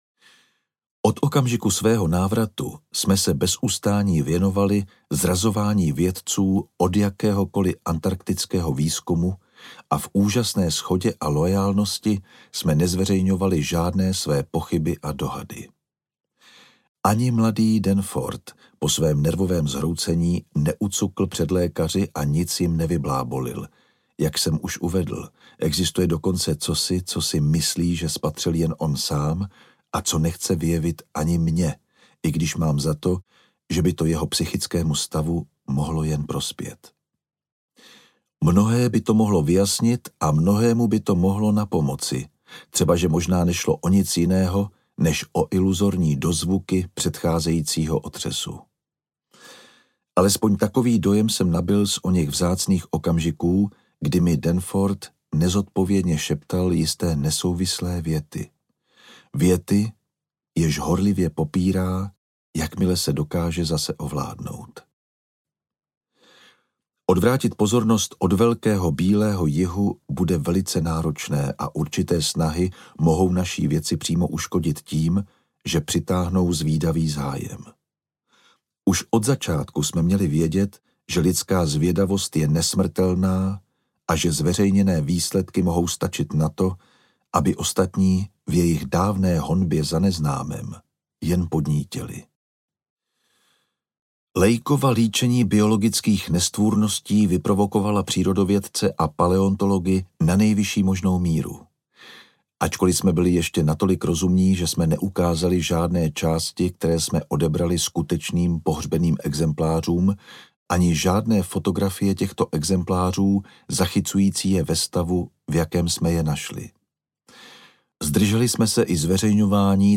V horách šílenství audiokniha
Ukázka z knihy
• InterpretJan Vondráček
v-horach-silenstvi-audiokniha